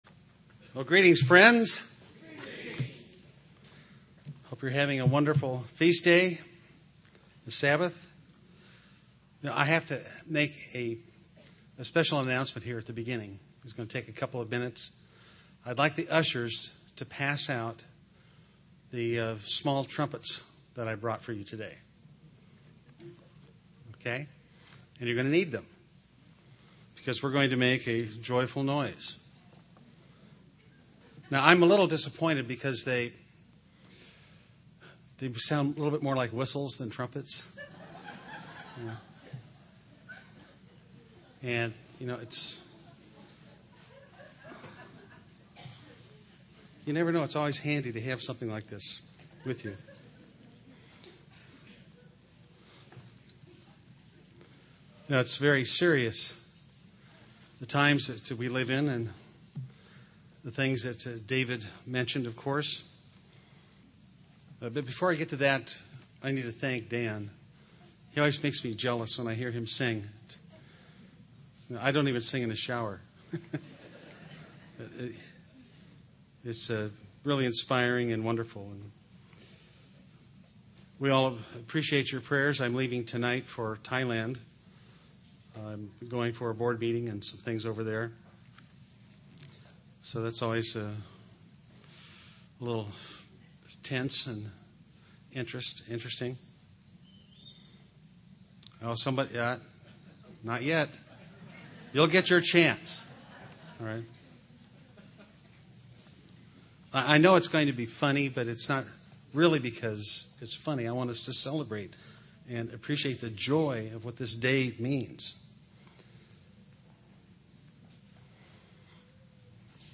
Given in Orange County, CA
UCG Sermon Studying the bible?